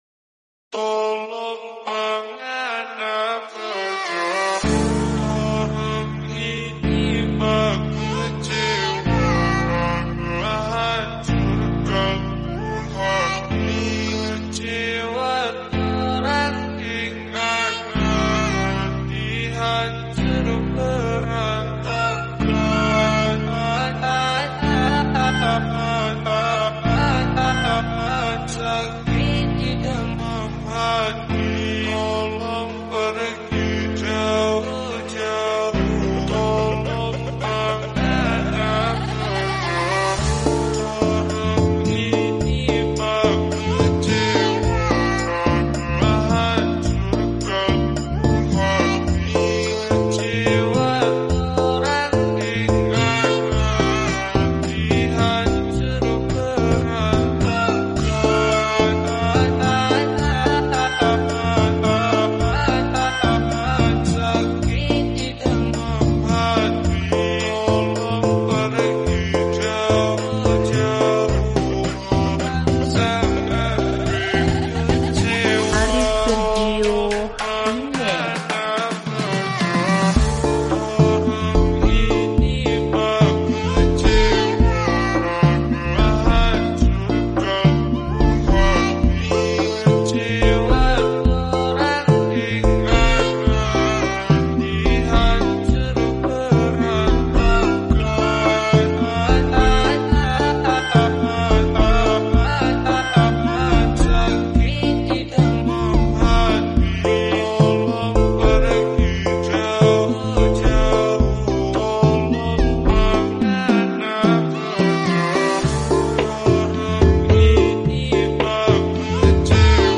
slowedandreverb